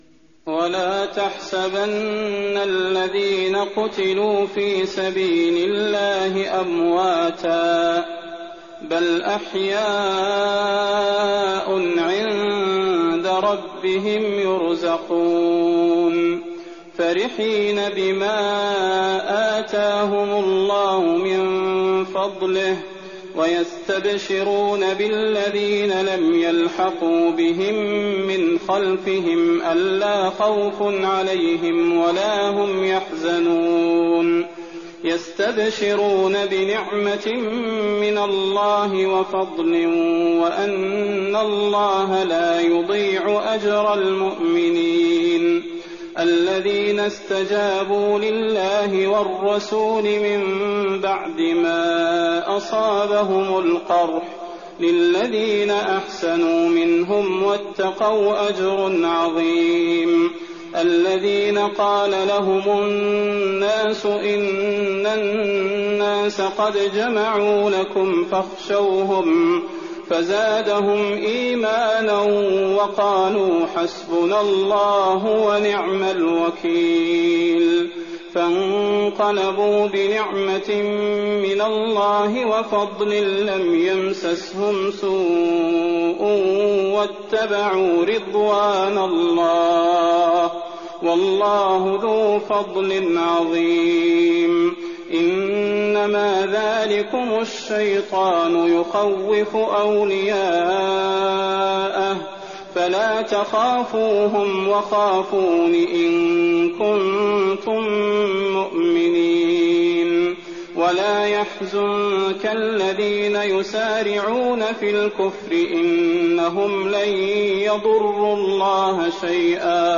تراويح الليلة الرابعة رمضان 1419هـ من سورتي آل عمران (169-200) و النساء (1-24) Taraweeh 4th night Ramadan 1419H from Surah Aal-i-Imraan and An-Nisaa > تراويح الحرم النبوي عام 1419 🕌 > التراويح - تلاوات الحرمين